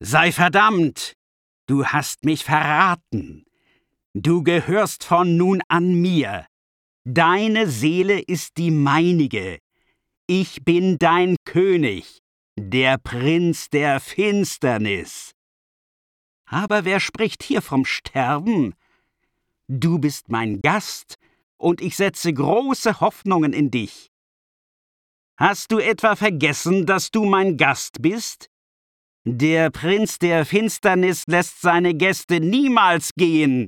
Hörproben Hörspiele
36Prinz-der-Finsternis-Hoerspiel.mp3